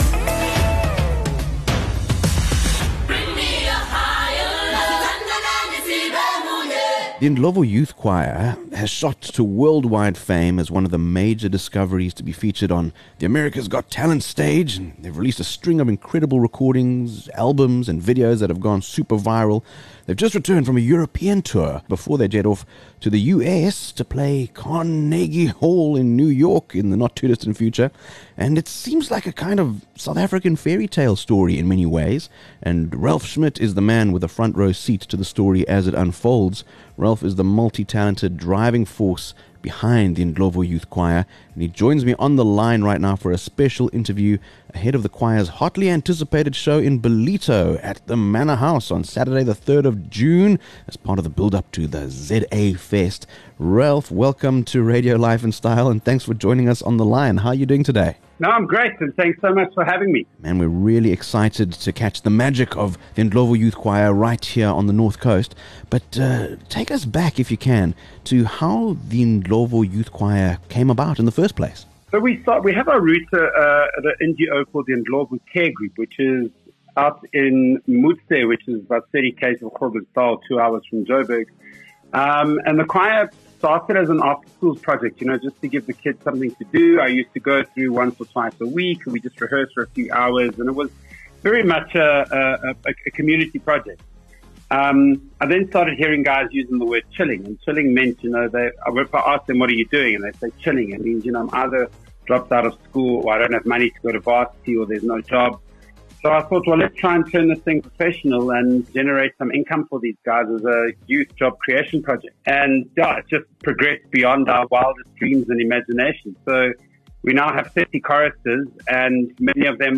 24 May Ndlovu Youth Choir Coming To Ballito: An Interview